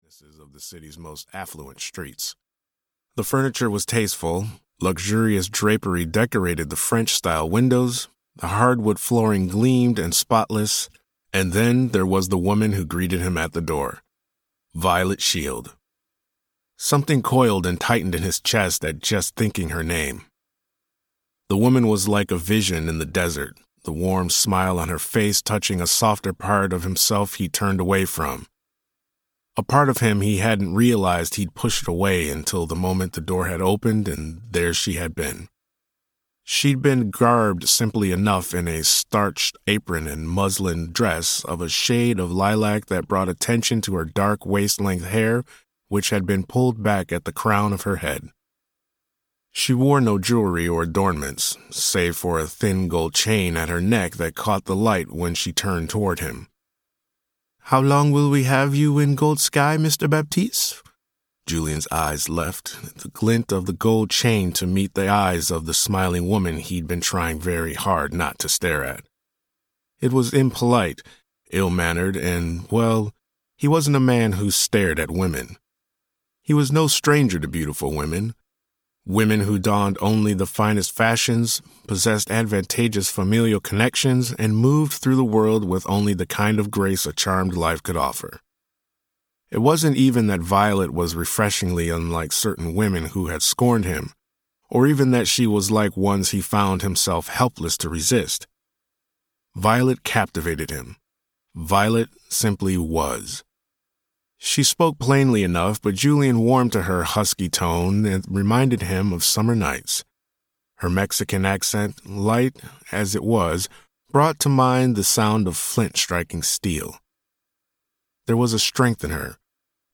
Hearth and Home (EN) audiokniha
Ukázka z knihy